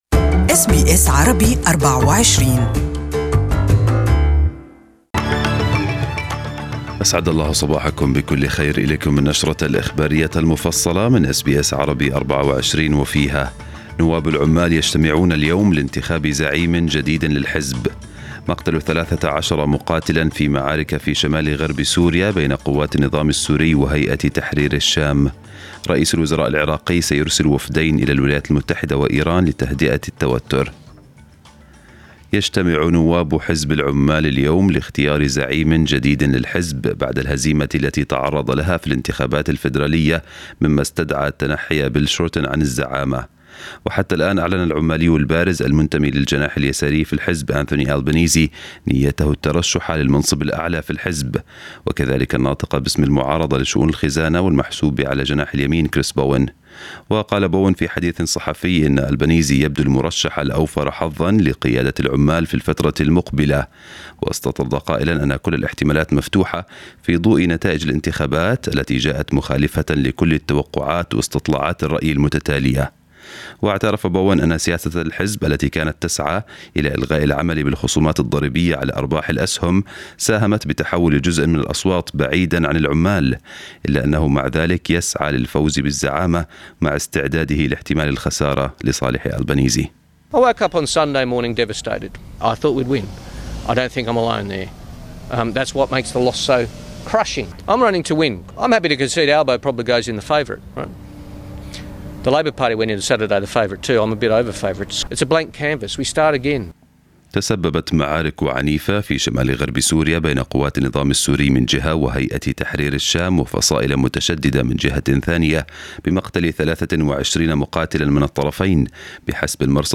Morning news bulletin in Arabic 22/5/2019